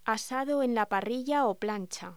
Locución: Asado en parrilla o plancha